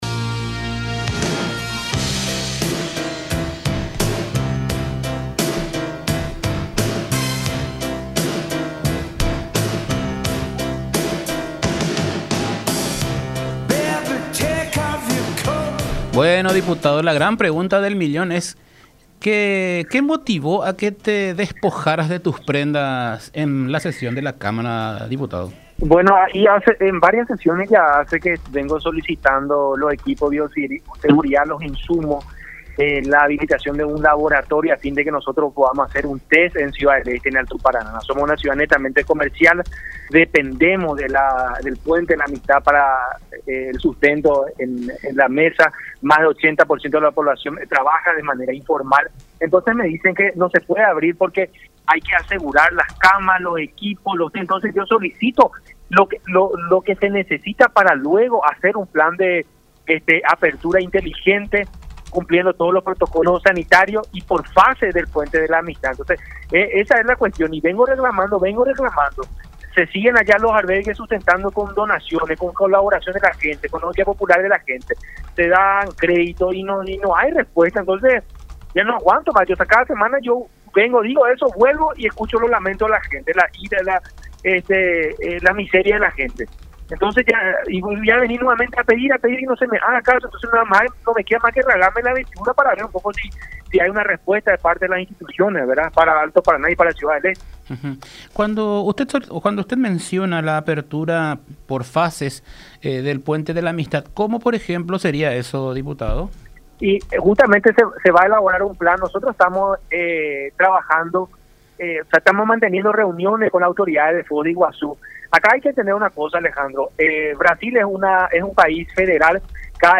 Jorge Brítez, diputado independiente.
“No me quedó otra cosa que rasgarme las vestiduras, como se dice, porque no se me escucha”, explicó el diputado independiente en contacto con La Unión.